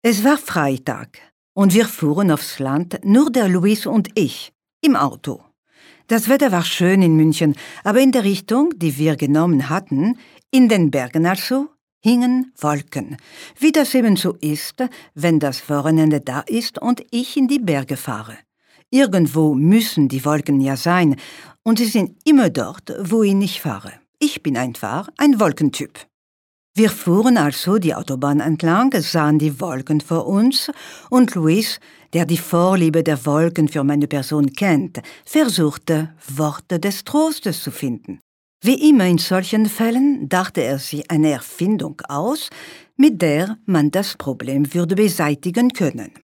Erfahrene warme französische Stimme.
Kein Dialekt
Sprechprobe: Sonstiges (Muttersprache):